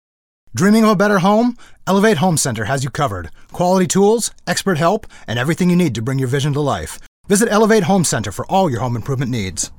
Male
Radio Commercials
Words that describe my voice are Warm, natural, enaging.